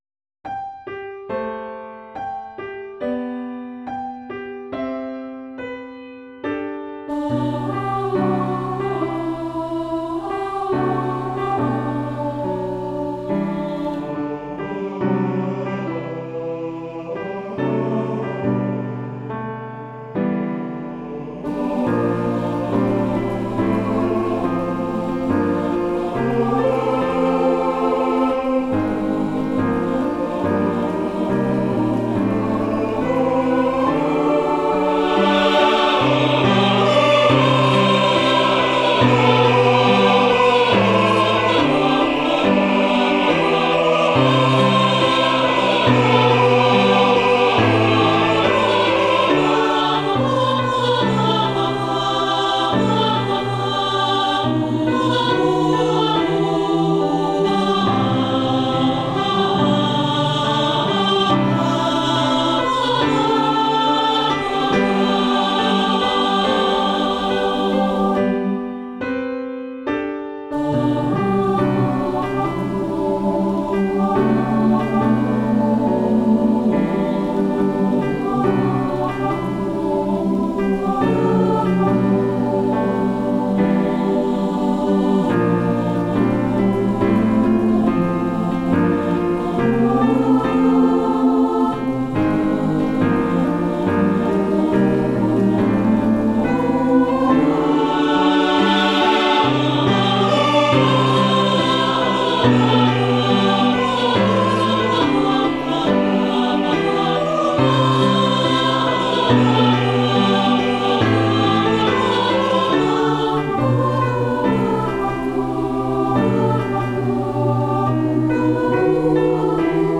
A simple SAB arrangement using the original Hymnbook piano arragnement
Voicing/Instrumentation: SAB , Youth Choir Mixed Or Unison We also have other 2 arrangements of " Because ".